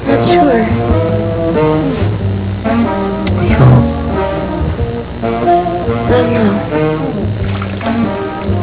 Classical/Instrumental
Comment: spy